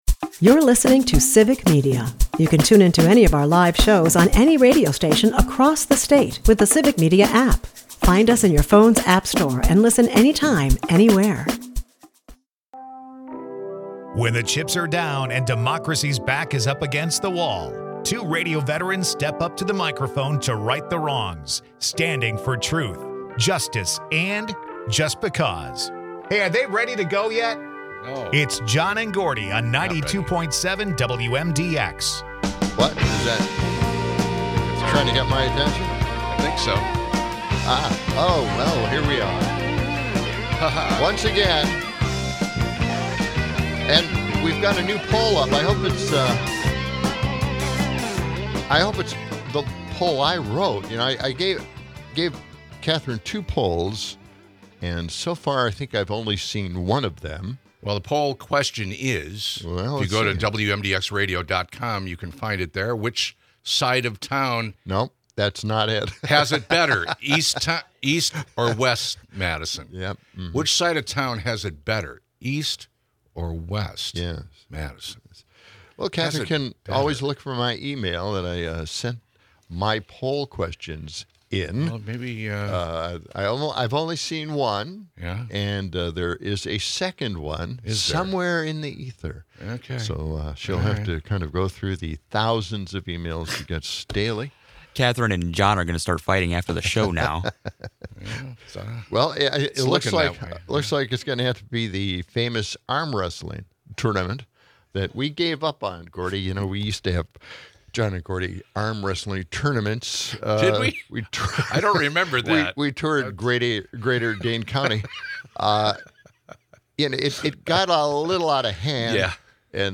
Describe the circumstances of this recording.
With a sprinkle of listener interaction and hypothetical battles between cartoon worlds and wild animals, the episode blends lighthearted banter with serious discussions on Trump's influence and Kamala Harris's prophetic warnings.